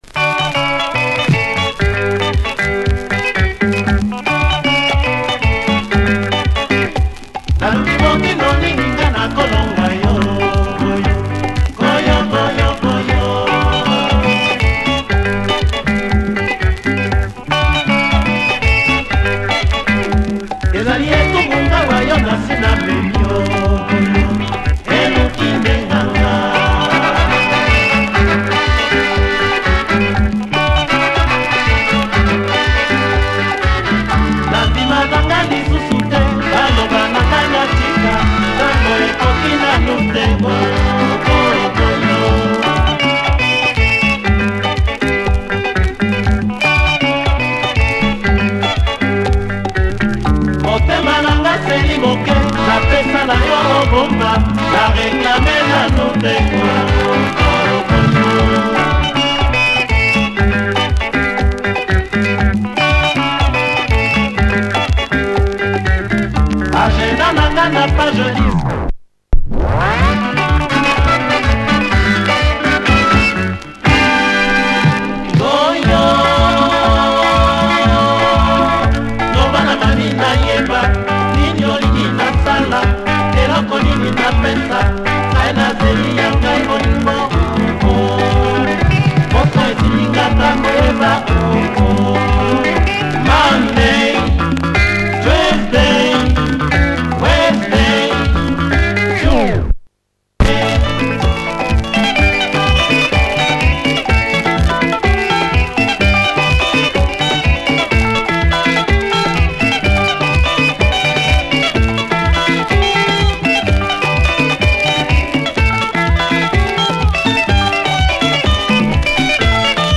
Could need another wash, some dirt still in the rills